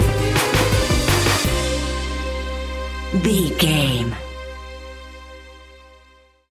Aeolian/Minor
Fast
World Music
percussion